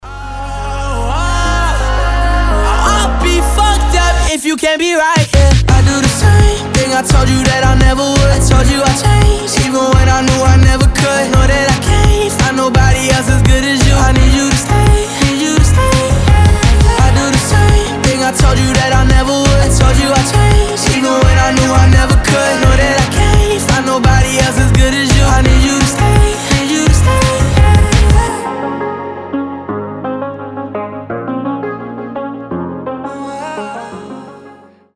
• Качество: 320, Stereo
красивый мужской голос
Synth Pop
дуэт